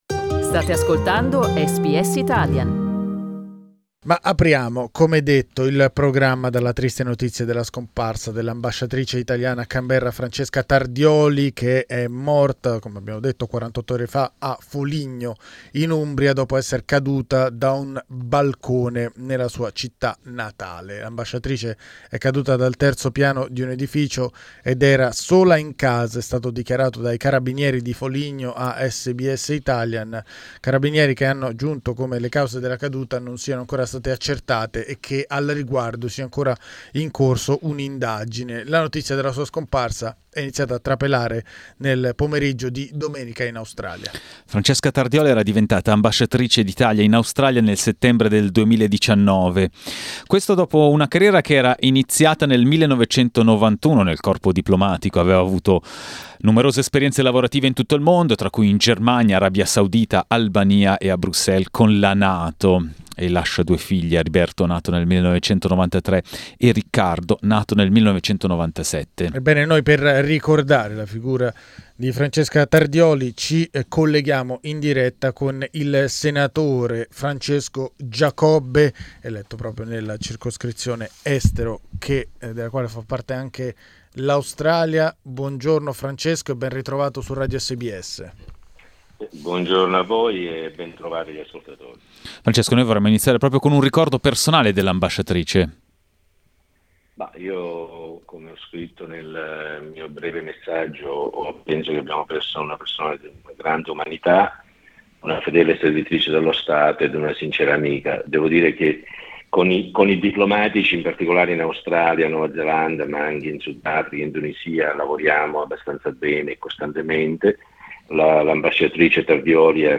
Il senatore Francesco Giacobbe ha ricordato ai nostri microfoni l'ambasciatrice Francesca Tardioli, recentemente scomparsa a Foligno.